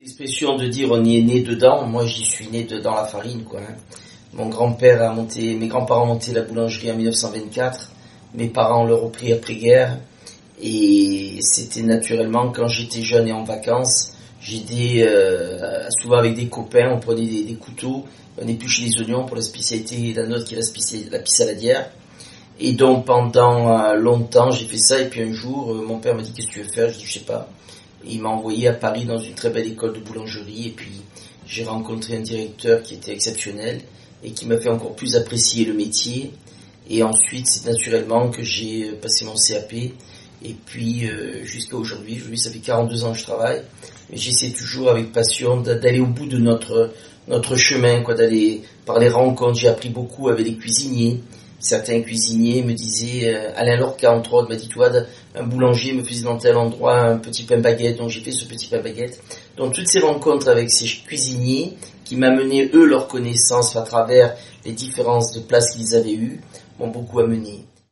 Les interviews de la série sont enregistrées en vidéo 3D haute définition.